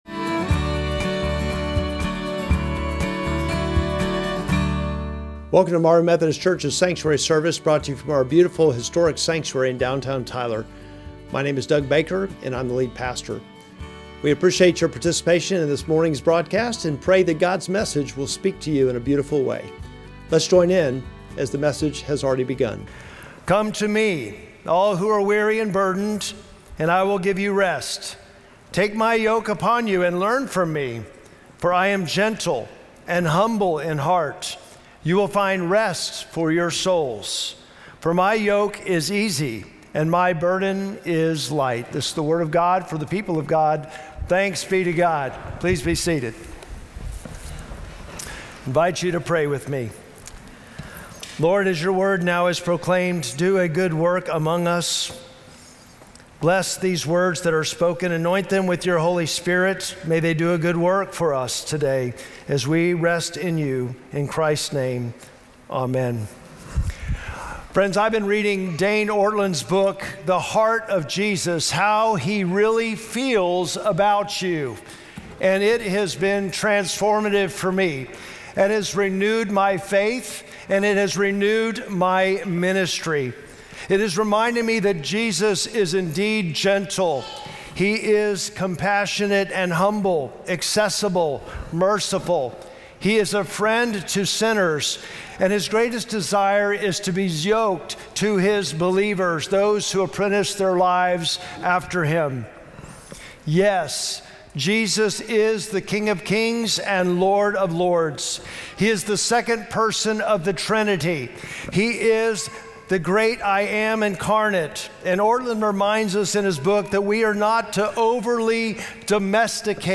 Sermon text: Matthew 11:28-30
Traditional Sermons